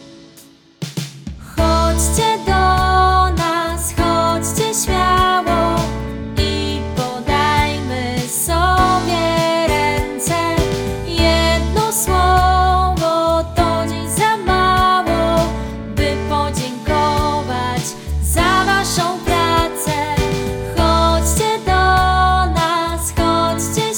Produkt zawiera utwór w wersji wokalnej oraz tekst. https